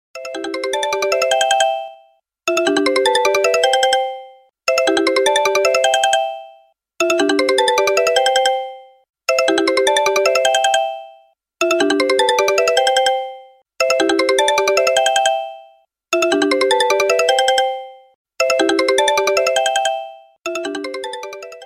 Ringtones Category: Message